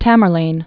(tămər-lān) or Tam•bur•laine(-bər-)OriginallyTimur.1336-1405.